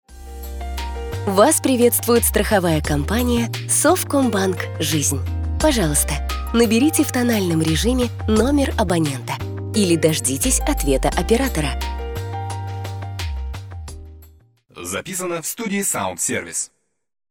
Женщина
Молодой
АТС
быстрый
Приглушенный
Средний